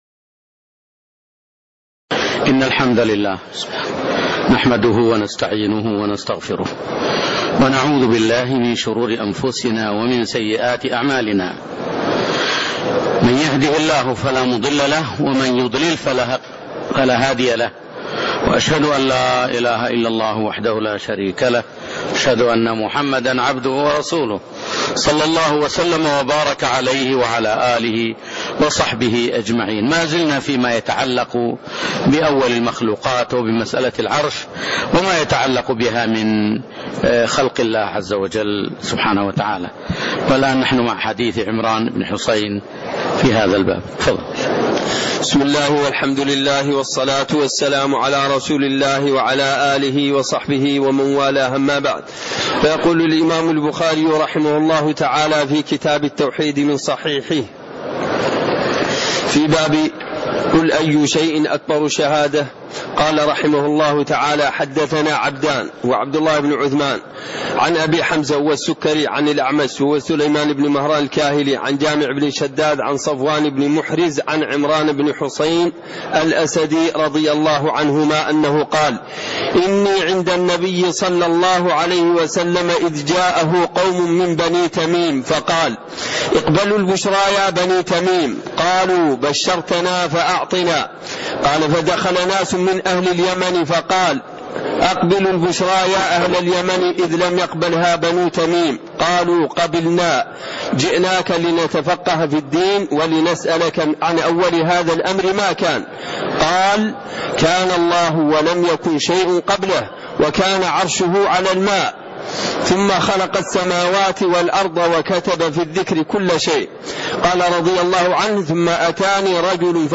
تاريخ النشر ١٥ ربيع الأول ١٤٣٤ هـ المكان: المسجد النبوي الشيخ